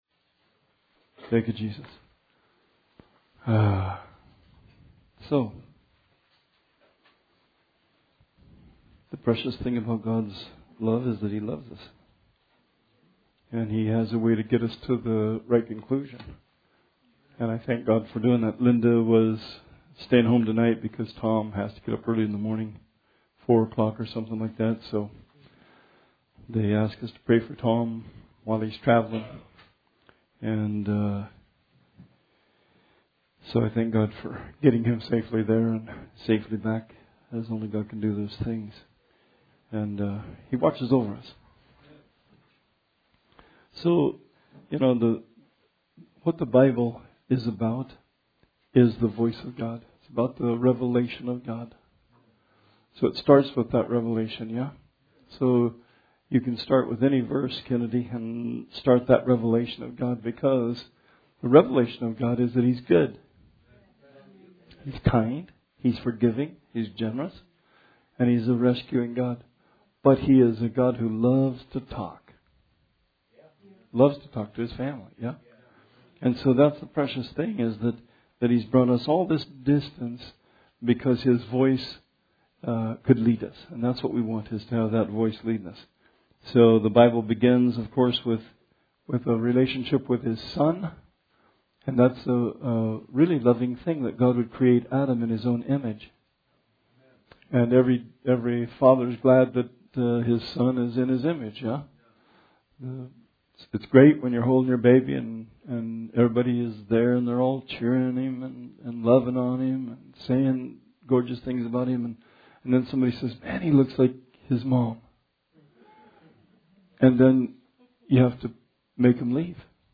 Bible Study 1/8/20